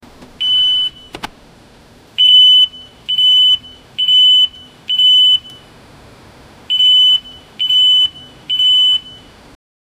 Royalty-free facility sound effects
facility-intercom-turning-mrh7gmze.wav